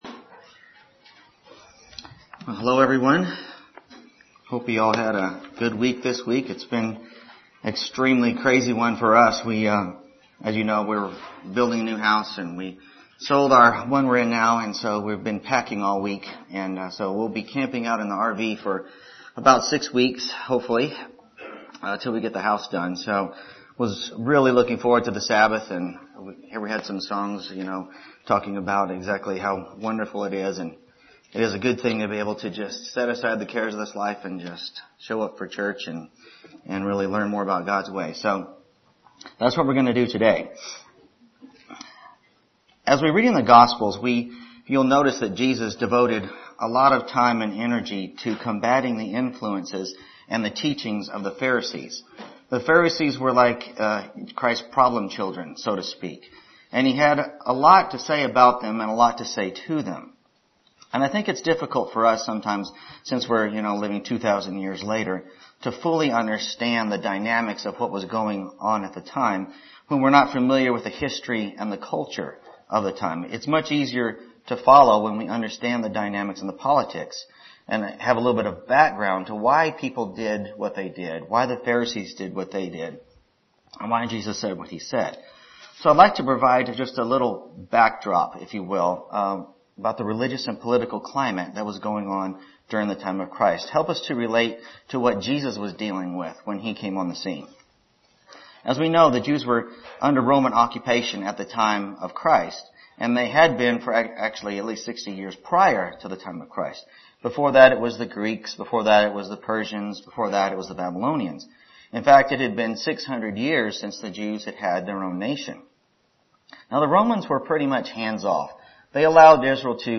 Sermons
Given in Lubbock, TX